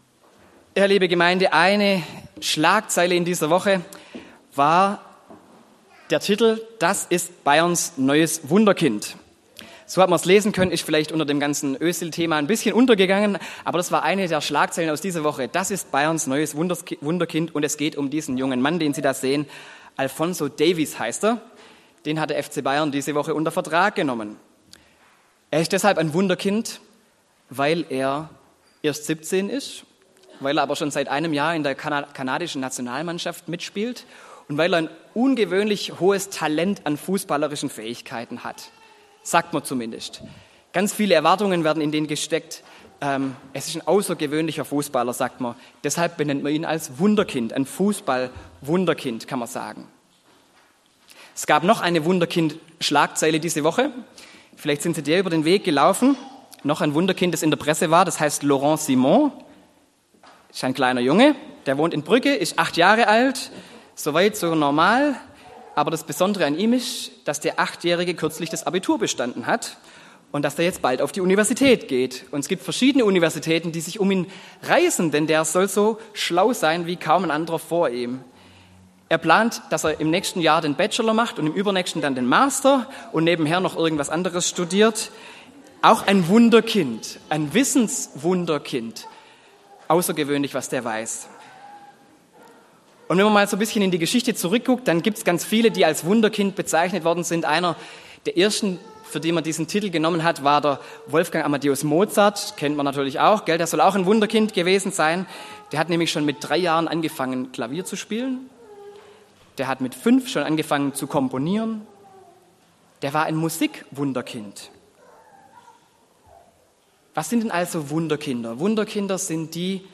Predigt zum Sommerkino-Film „Wunder“: Bist du ein Wunderkind oder für Wunder blind?